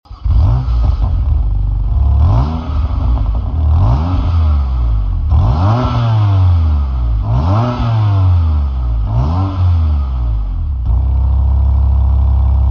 Listen to it purring!
• M2 CS Dual-Branch Exhaust System:
• 3.0 Bi-Turbo Twin-Power Straight Six S55 Engine
BMW-M2-CS-Alpine-White-Exhaust-Revs.mp3